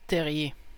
Ääntäminen
Canada (Montréal) - terrier: IPA: /tɛ.ʁje/